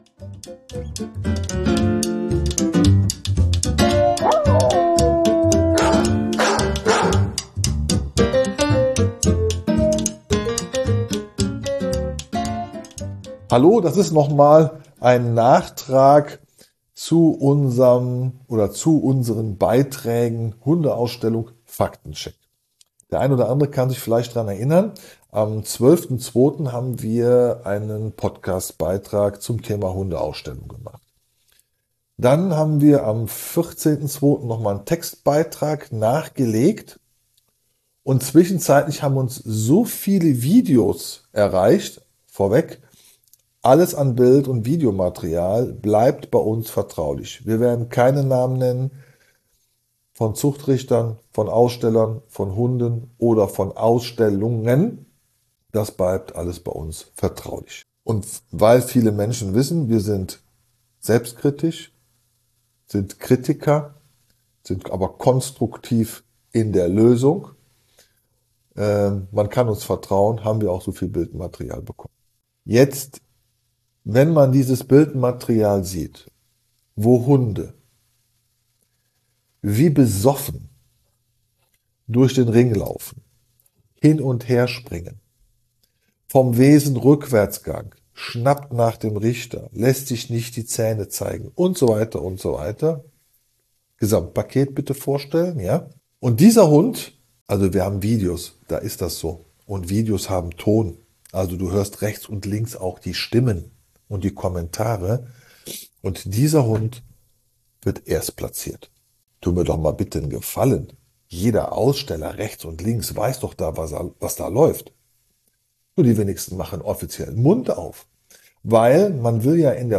Aber ich fand es schön, dass ich mit mir selbst darüber gesprochen habe.